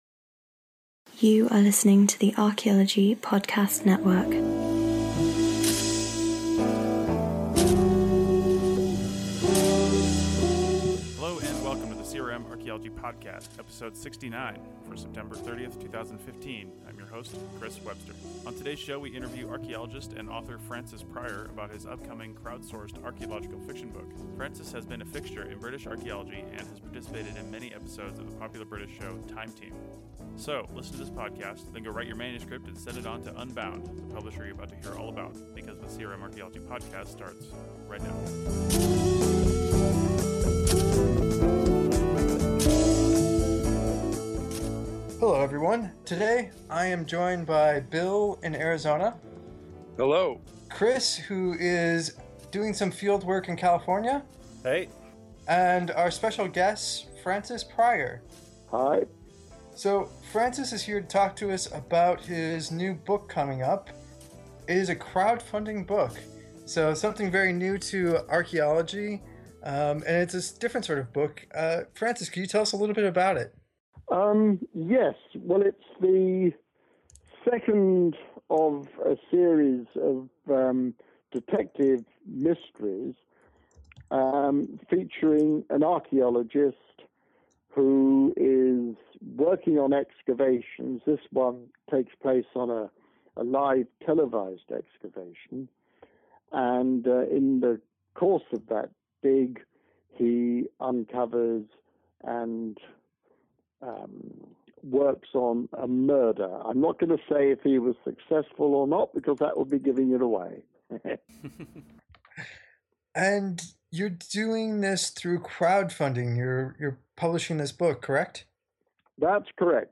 On today's show we interview British archaeologist and author, Francis Pryor.